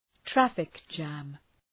Προφορά